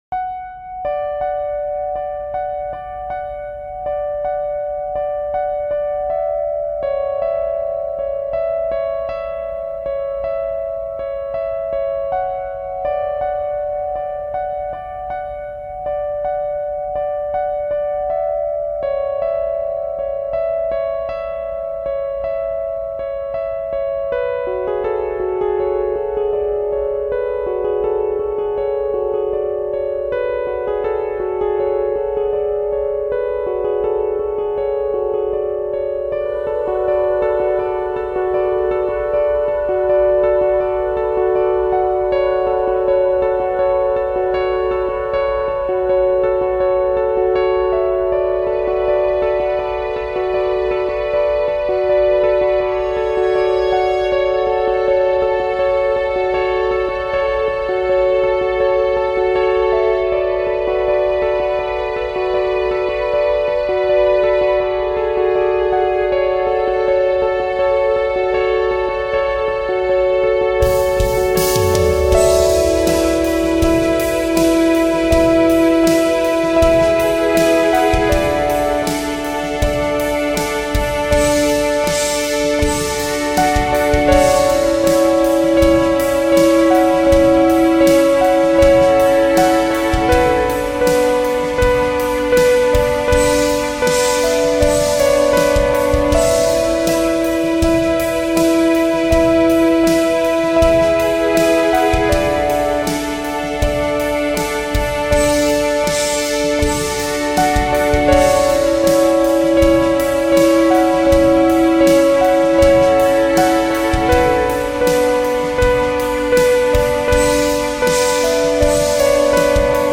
playing around on piano in F# minor